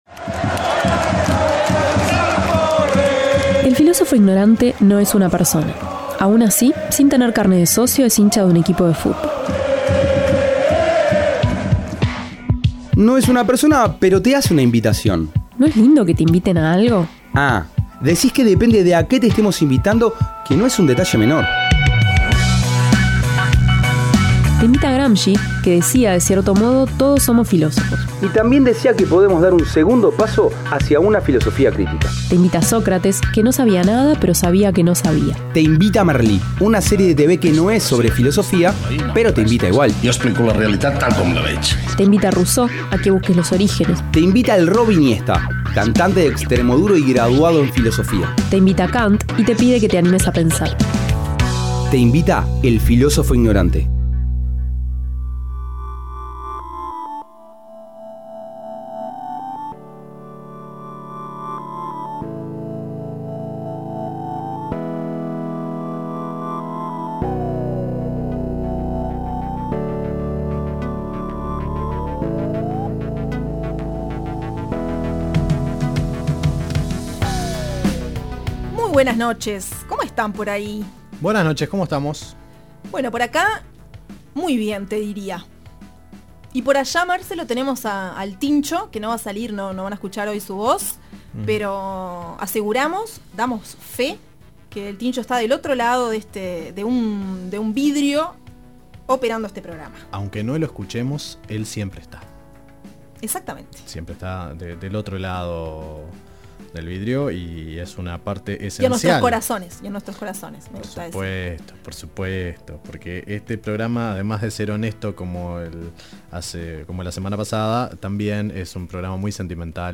También acompañó la palabra de Hannah Arendt, y sonaron bandas musicales con canciones alusivas al tema.